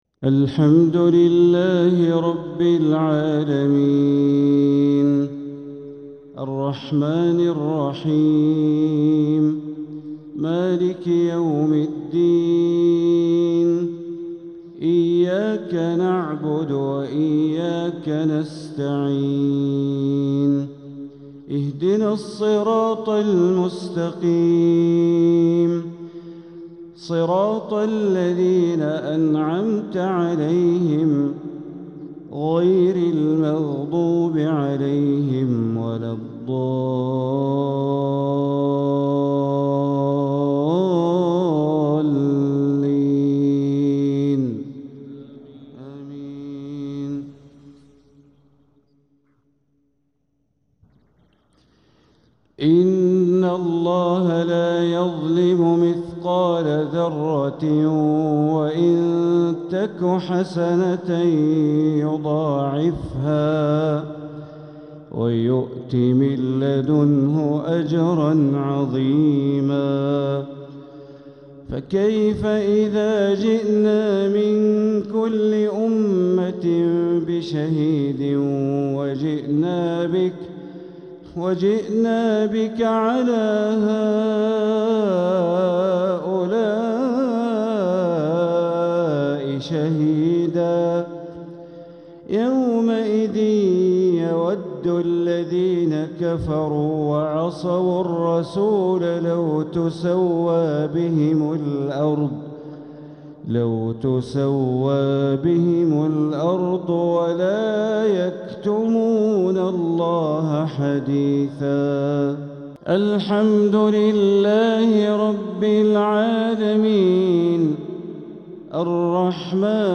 تلاوة من سورتي النساء والأعراف | مغرب الخميس ٣ربيع الآخر ١٤٤٧ > 1447هـ > الفروض - تلاوات بندر بليلة